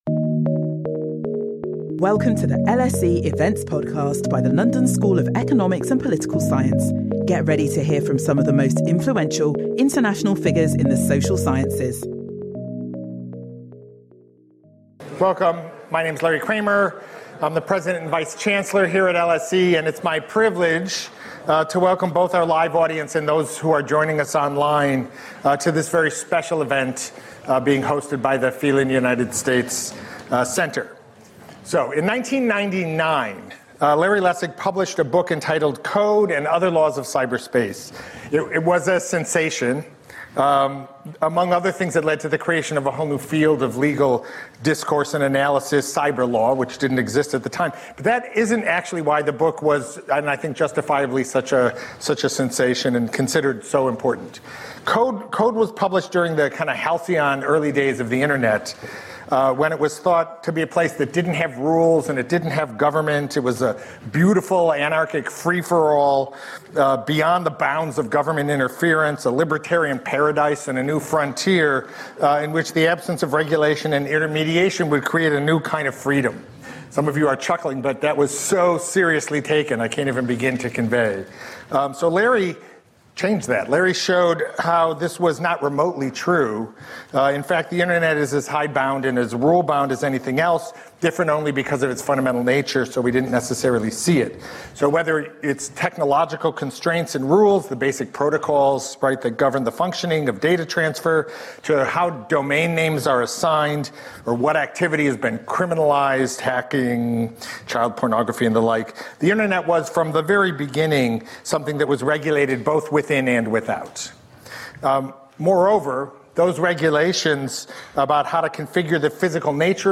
In this lecture, Lawrence Lessig will discuss the impact of artificial intelligence on the 2024 American election, and the implications that this will have for democracy in the future.